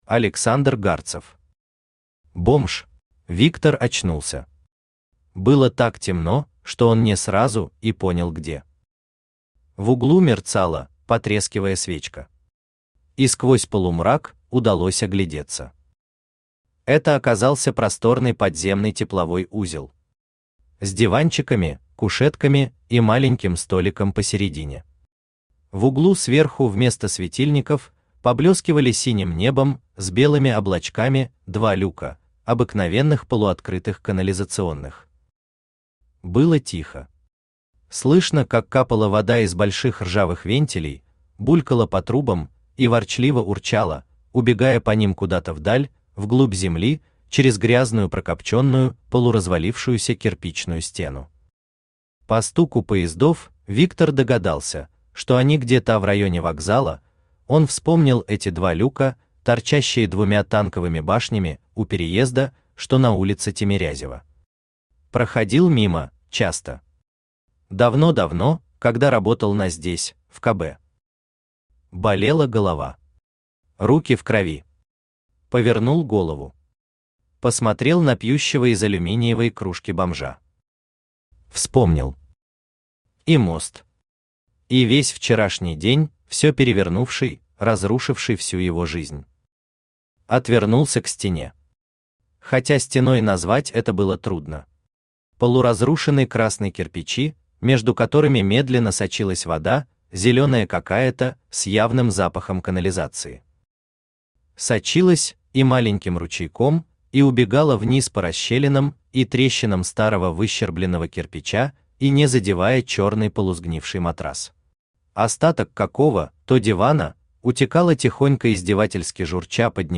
Аудиокнига Бомж | Библиотека аудиокниг
Aудиокнига Бомж Автор Александр Гарцев Читает аудиокнигу Авточтец ЛитРес.